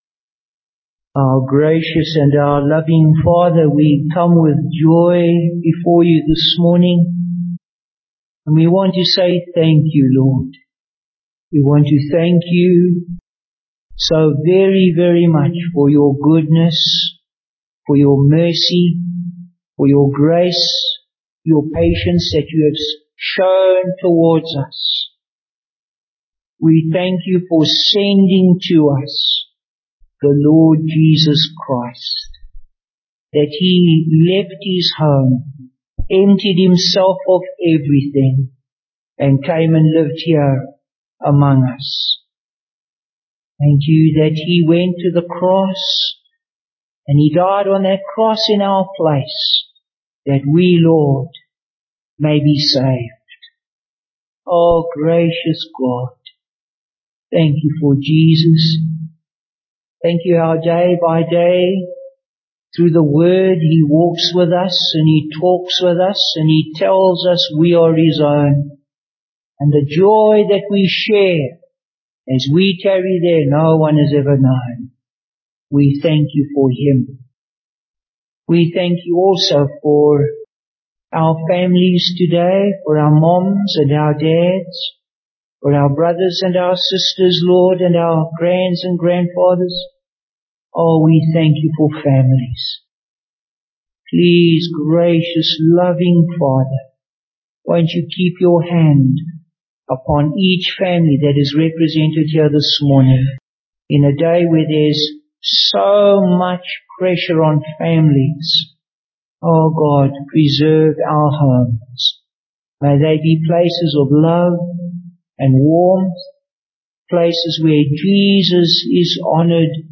Bible Text: Ephesians 1:3-14 | Preacher: Bishop Warwick Cole-Edwards | Series: Ephesians